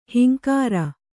♪ hinkāra